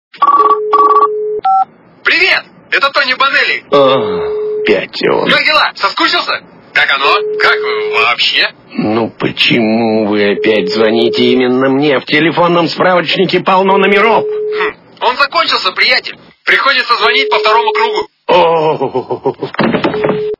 Голос - Привет, как дела соскучился как оно как вообще Звук Звуки Голос - Привет, как дела соскучился как оно как вообще
» Звуки » Смешные » Голос - Привет, как дела соскучился как оно как вообще
При прослушивании Голос - Привет, как дела соскучился как оно как вообще качество понижено и присутствуют гудки.